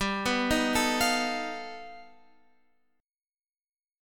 G Augmented 9th